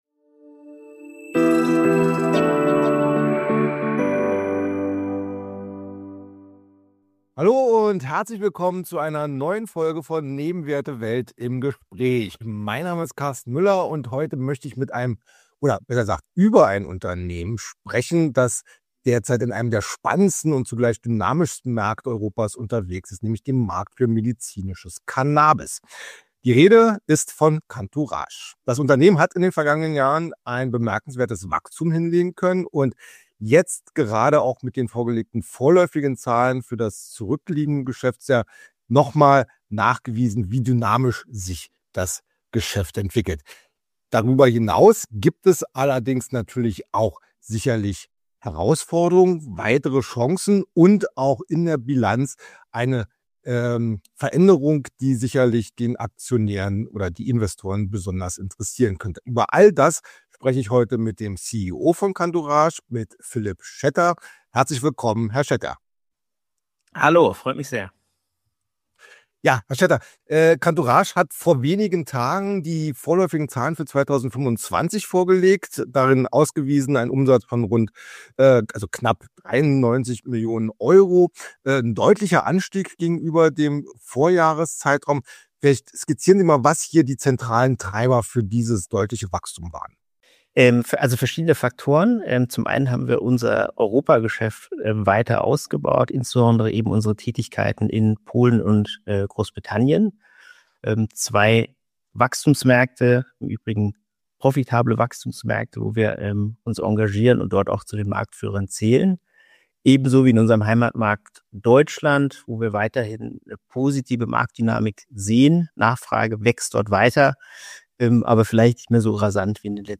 Cantourage wächst weiter dynamisch – doch wie nachhaltig ist dieser Kurs? Im Gespräch